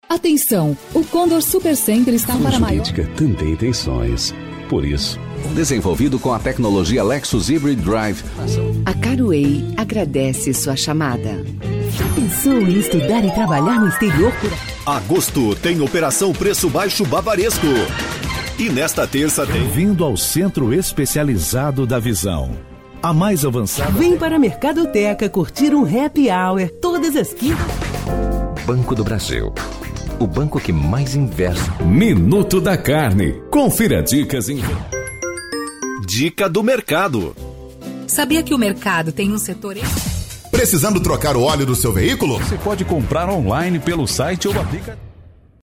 comercial1.mp3